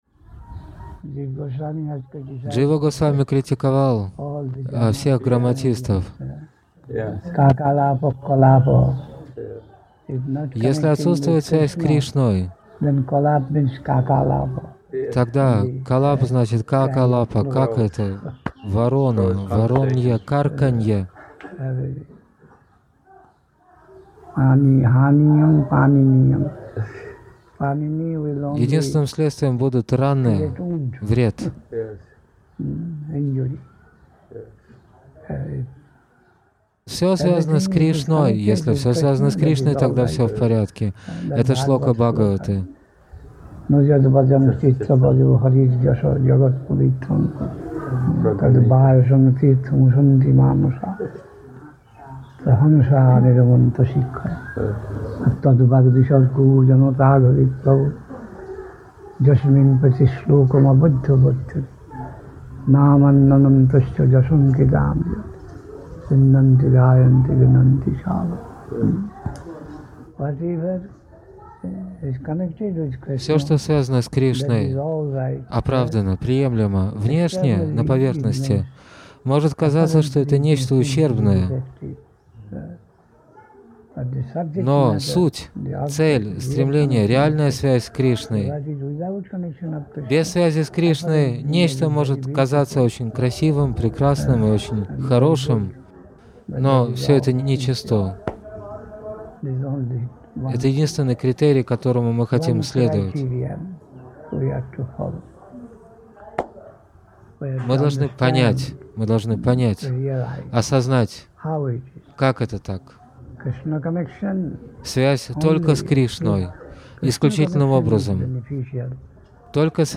(29 апреля 1982 года. Навадвипа Дхама, Индия)